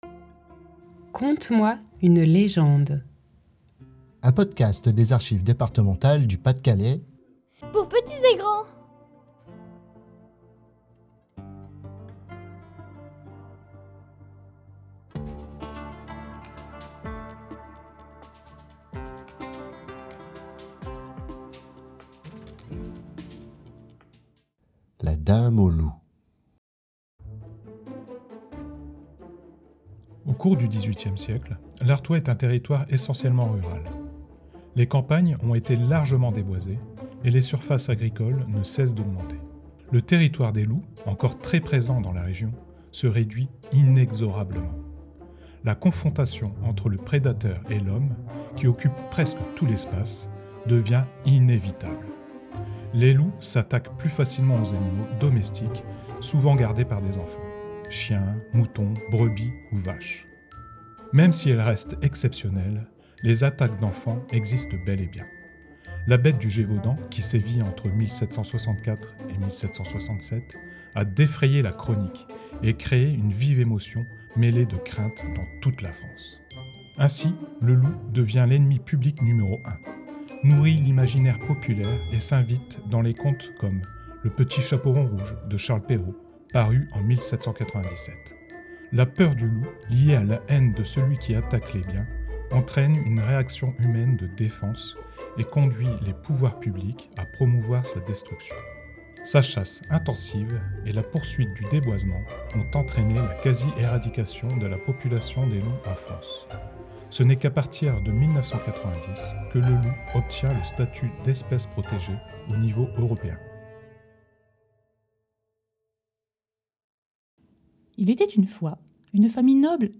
Un podcast pour petits et grands